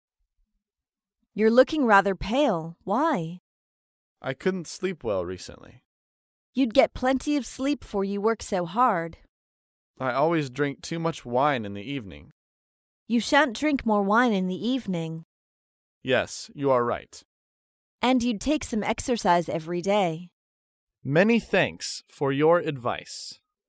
在线英语听力室高频英语口语对话 第139期:健康建议(2)的听力文件下载,《高频英语口语对话》栏目包含了日常生活中经常使用的英语情景对话，是学习英语口语，能够帮助英语爱好者在听英语对话的过程中，积累英语口语习语知识，提高英语听说水平，并通过栏目中的中英文字幕和音频MP3文件，提高英语语感。